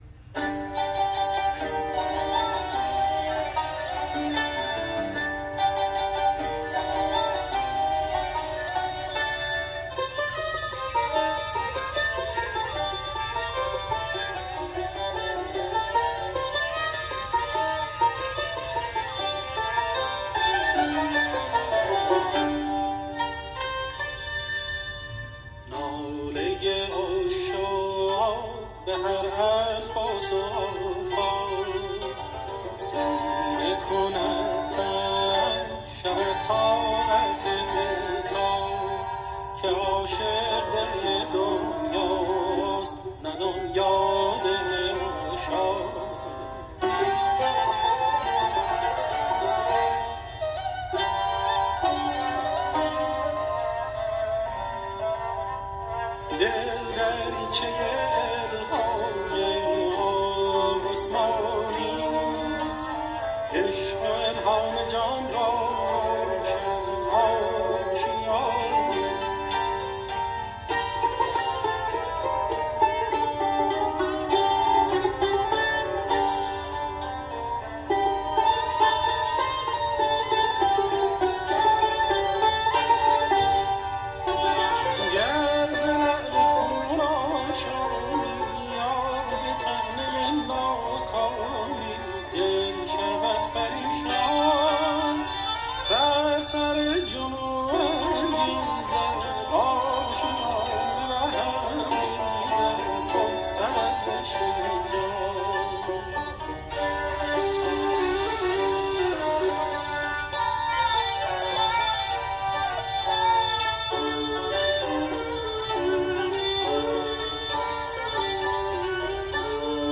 در مایه اصفهان